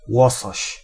Ääntäminen
IPA: /laks/